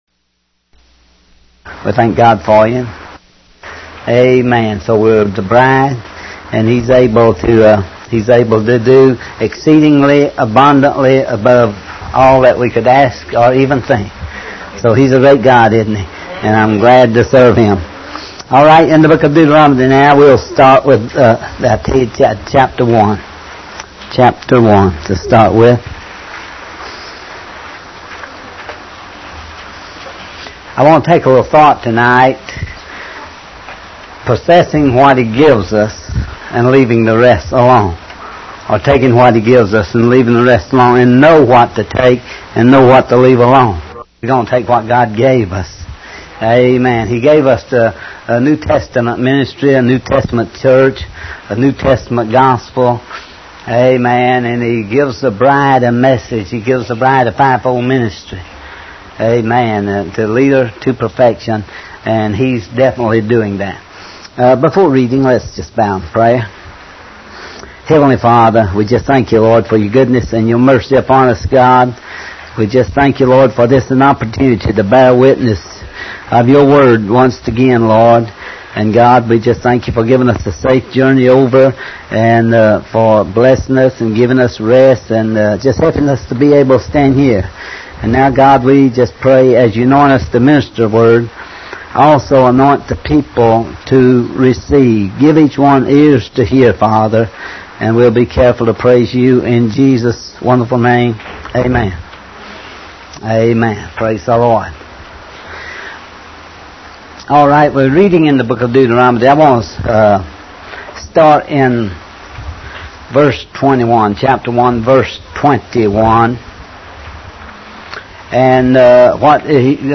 Taking Only What Belongs To Us And Leaving The Rest Alone - Singapore (1230) This book is the sermon preached in Singapore entitled, Taking Only What Belongs to Us and Leaving the Rest Alone.